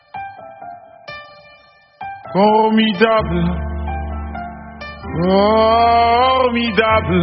formidable Meme Sound Effect
Category: Sports Soundboard